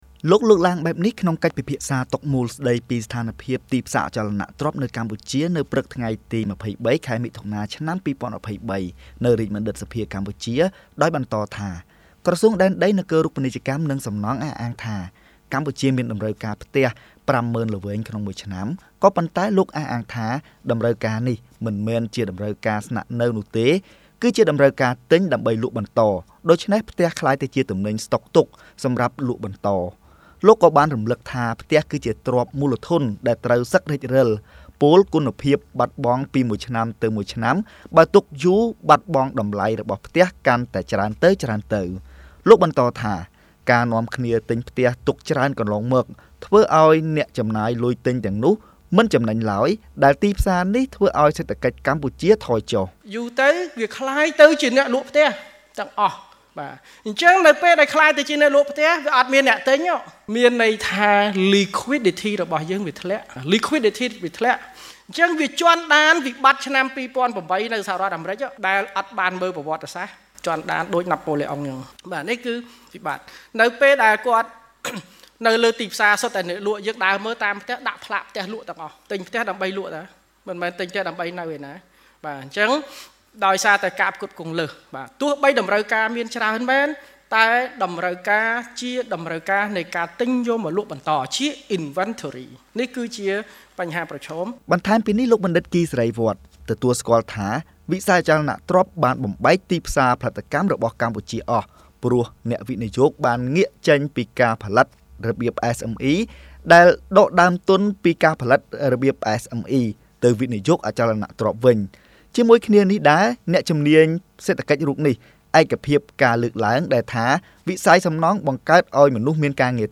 លោកលើកឡើងបែបនេះក្នុងកិច្ចពិភាក្សាតុមូល ស្តីពី «ស្ថានភាពទីផ្សារអចលនទ្រព្យនៅកម្ពុជា»នៅព្រឹកថ្ងៃទី២៣ ខែមិថុនា ឆ្នាំ២០២៣នេះ នៅរាជបណ្ឌិត្យសភាកម្ពុជាដោយបន្តថា ក្រសួងដែនដីនគររូបនីយកម្ម និងសំណង់អះអាងថា កម្ពុជាមានតម្រូវការផ្ទះ ៥ម៉ឺនល្វែងក្នុងមួយឆ្នាំ ក៏ប៉ុន្តែលោកអះអាងថា តម្រូវការនេះមិនមែនជាតម្រូវការស្នាក់នៅទេ គឺជាត្រូវការទិញដើម្បីលក់បន្ត ដូច្នេះផ្ទះក្លាយជាទំនិញស្ដុកទុកសម្រាប់លក់បន្ត។ លោកក៏បានរំលឹកថា ផ្ទះគឺជាទ្រព្យមូលធនដែលត្រូវសឹករិចរិល ពោលគុណភាពបាត់បង់ពីមួយឆ្នាំទៅមួយឆ្នាំ បើទុកយូរបាត់បង់តម្លៃរបស់ផ្ទះកាន់តែច្រើនទៅៗ។ លោកបន្តថា ការណ៍នាំគ្នាទិញផ្ទះទុកច្រើនកន្លងមកធ្វើឱ្យអ្នកចំណាយលុយទិញទាំងនោះមិនចំណេញឡើយ ដែលទីផ្សារនេះធ្វើឱ្យសេដ្ឋកិច្ចកម្ពុជាថយ។